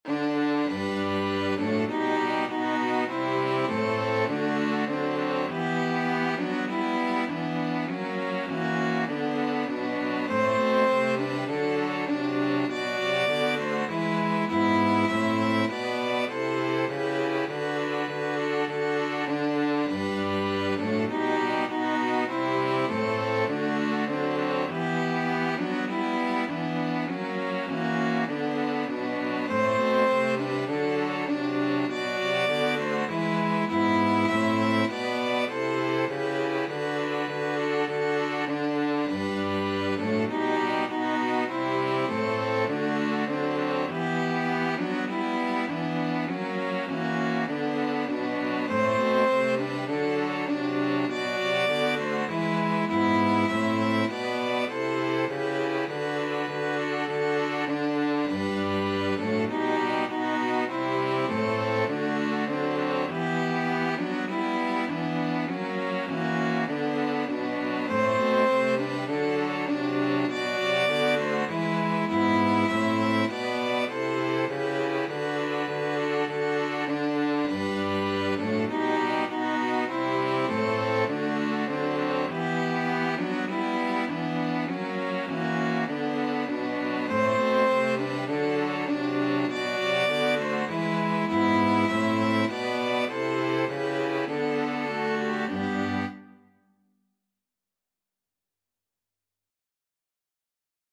Free Sheet music for String Quartet
Violin 1Violin 2ViolaCello
G major (Sounding Pitch) (View more G major Music for String Quartet )
4/4 (View more 4/4 Music)
Traditional (View more Traditional String Quartet Music)
i_heard_the_bells_v2_STRQ.mp3